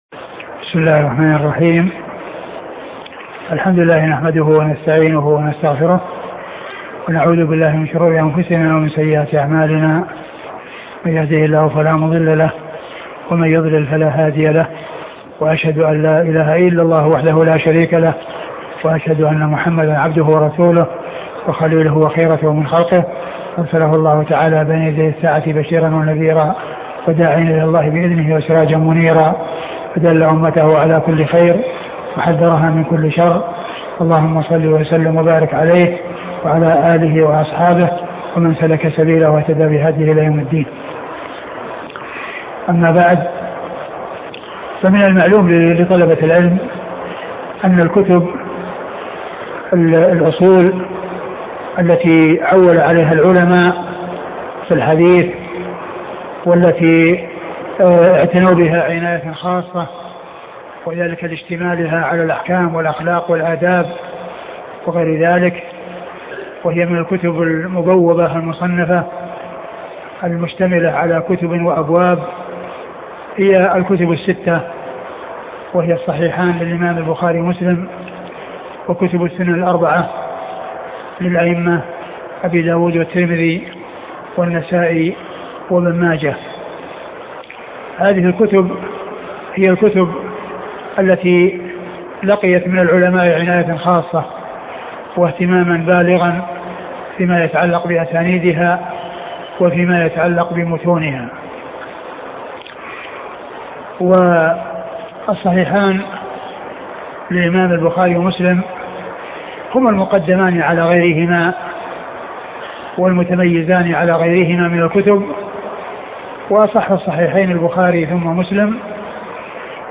شرح سنن الترمذي الدرس الأول